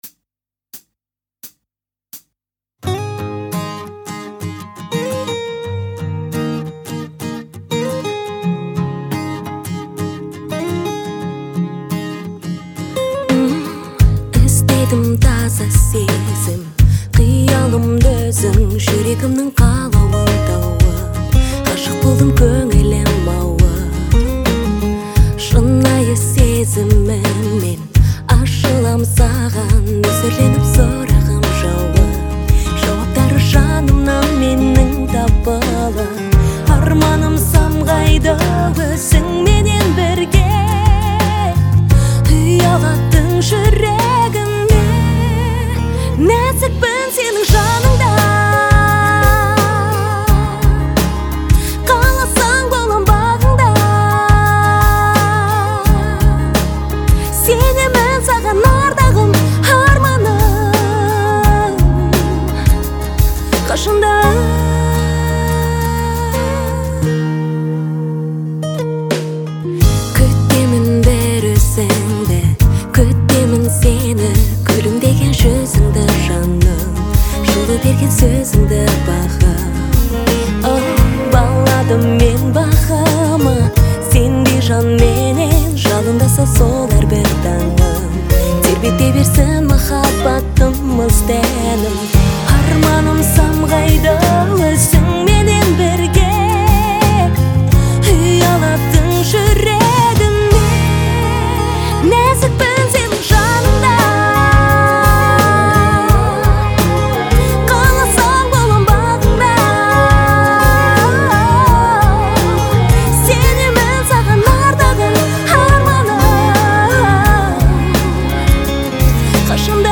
это яркий образец казахской поп-музыки
обладая выразительным голосом